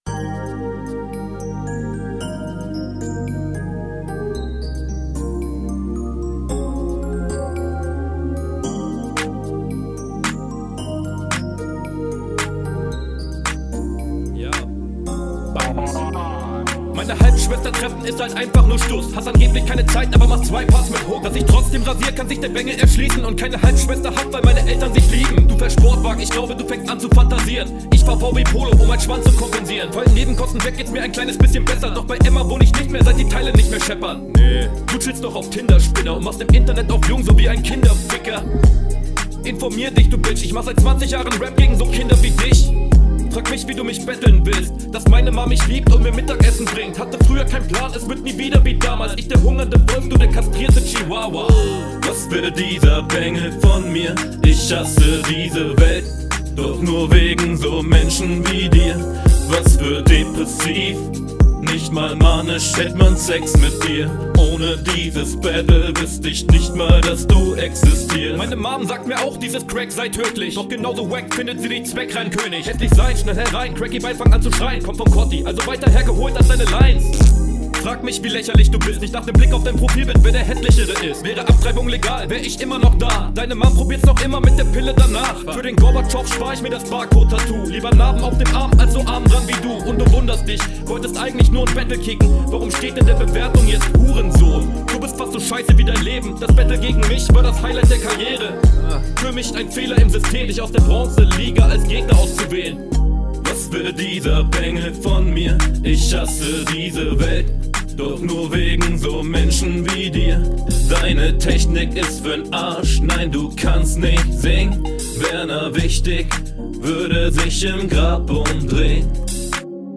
Stimme ser leise abgemischt leider.